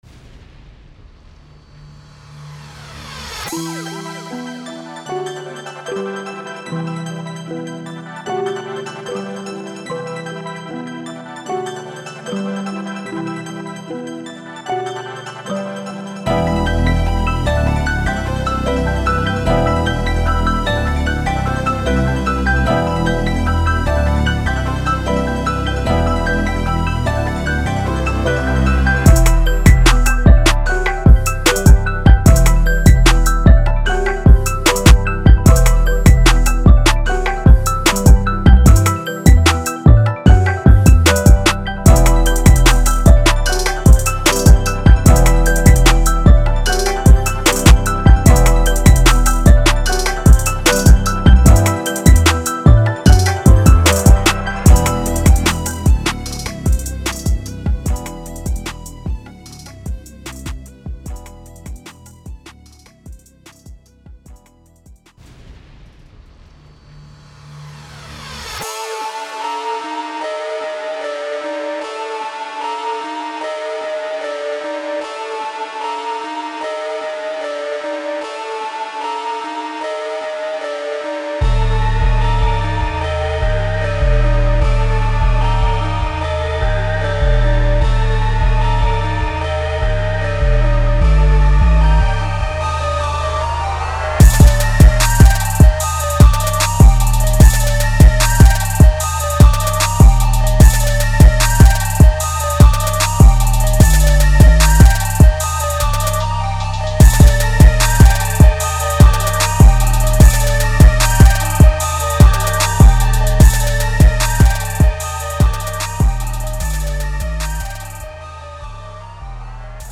3. Trap